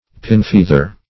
Search Result for " pinfeather" : The Collaborative International Dictionary of English v.0.48: Pinfeather \Pin"feath`er\, n. A feather not fully developed; esp., a rudimentary feather just emerging through the skin.
pinfeather.mp3